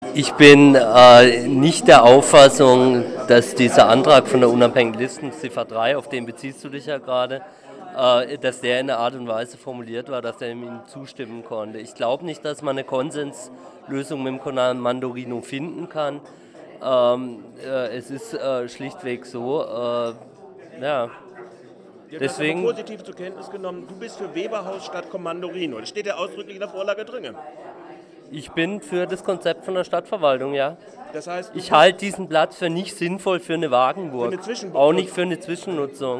Ein Studiogespräch